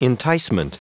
Prononciation du mot enticement en anglais (fichier audio)
Prononciation du mot : enticement